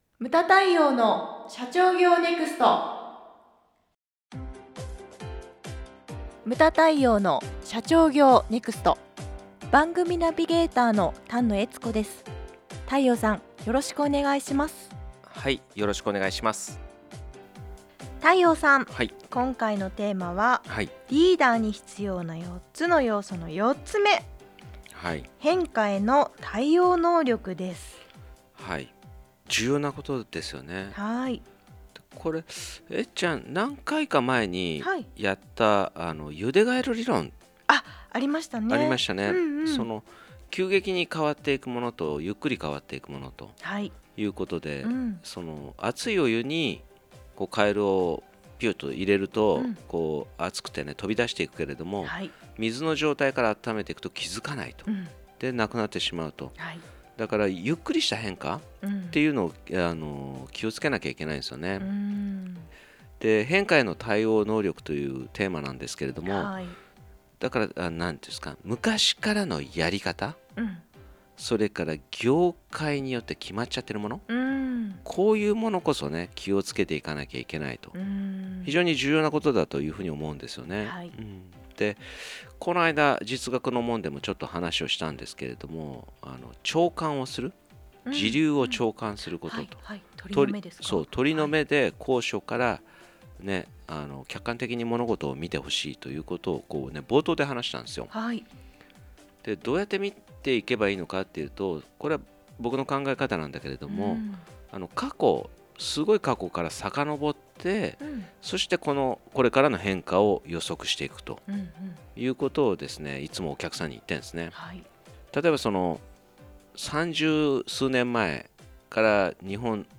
↑音声コラムは上記を再生ください↑